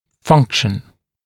[‘fʌŋkʃ(ə)n] [‘фанкш(э)н] функция; функционировать